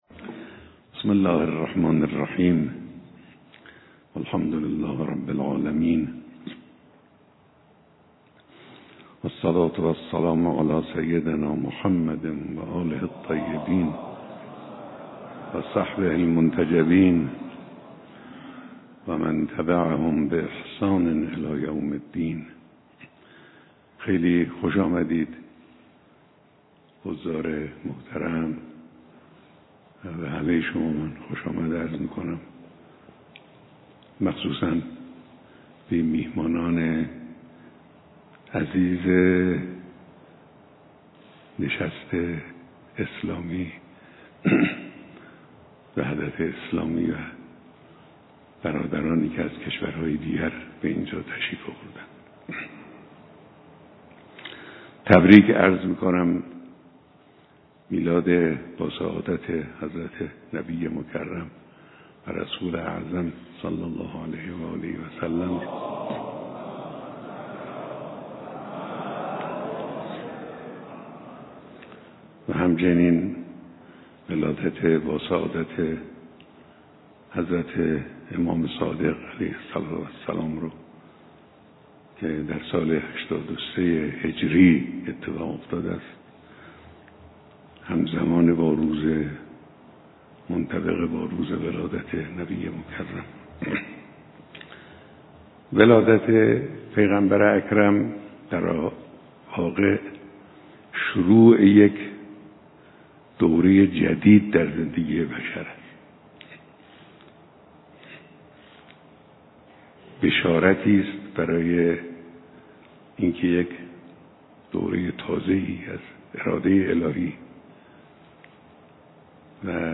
بیانات رهبر انقلاب در دیدار امروز میهمانان کنفرانس وحدت اسلامی و جمعی از مسئولان نظام.mp3
بیانات-رهبر-انقلاب-در-دیدار-امروز-میهمانان-کنفرانس-وحدت-اسلامی-و-جمعی-از-مسئولان-نظام.mp3